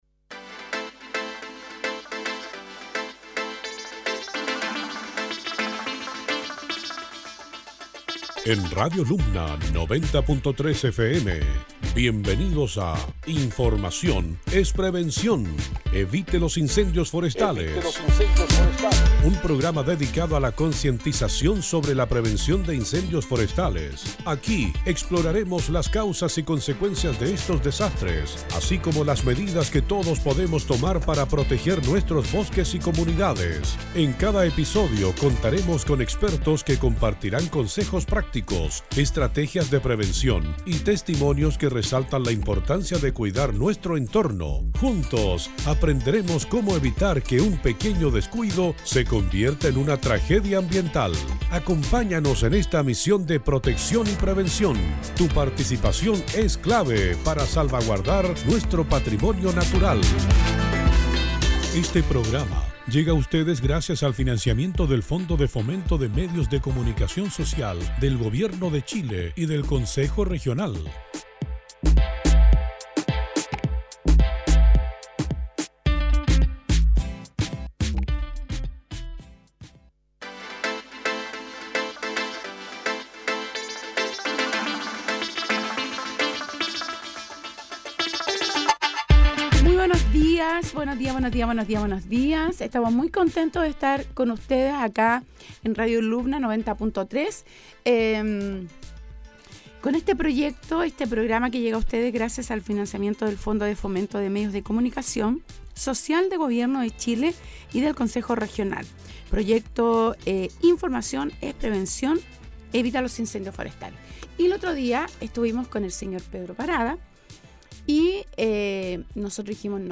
Durante este capítulo tuvimos la oportunidad de entrevistar